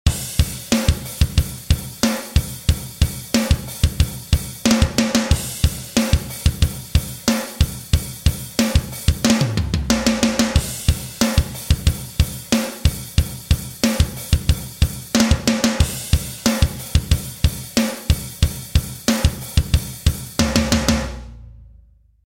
Powerful, studio-grade rock drums perfect for energetic, driving modern rock or metal. Captured with vintage mics and processed through analog gear for desert smack.
Snare Nu (Drums)
Recorded at Fireside Sound in Joshua Tree, California during the making of Silverstein's albums Antibloom and Pink Moon.
dd-snare-nu-drums.Ba8NB46P.mp3